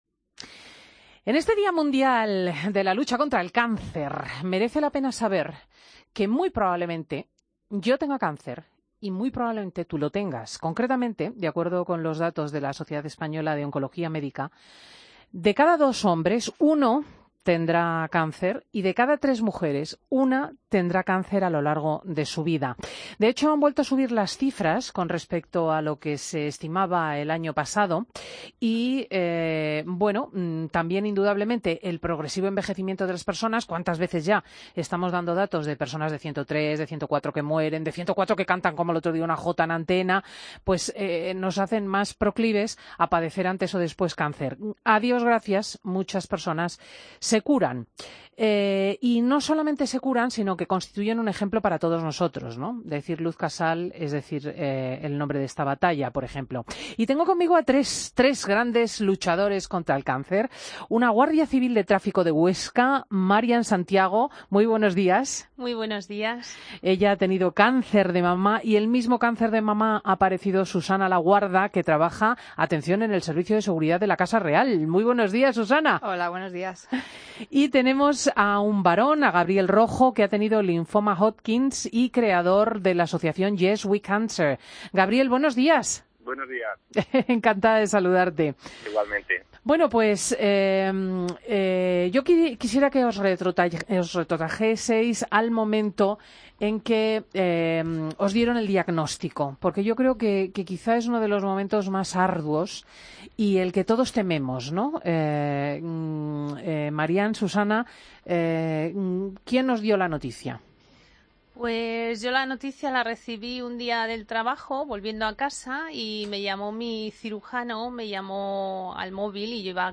AUDIO: Tres personas que han logrado vencer el cáncer le cuentan a Cristina López Schlichting su experiencia.